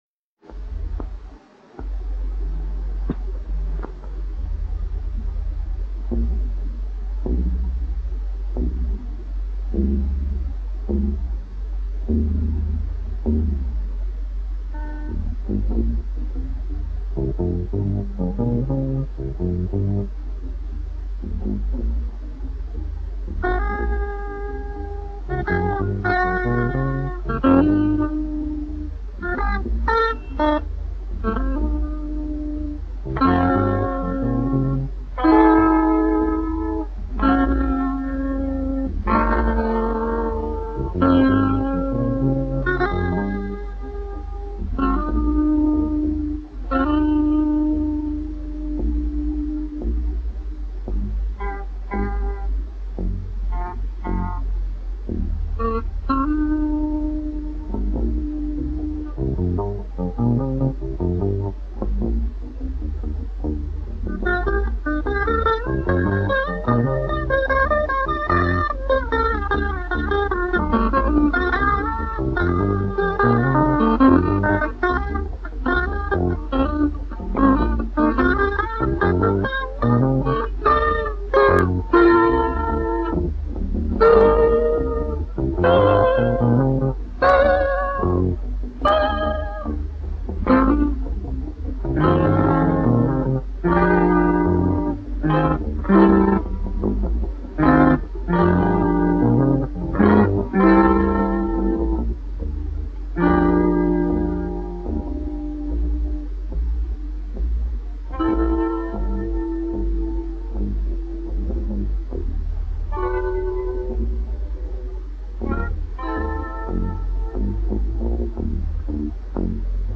Записано в феврале 1973 года в ЛГУ на факультете ПМиПУ
Очень смешно, но очень плохо записано".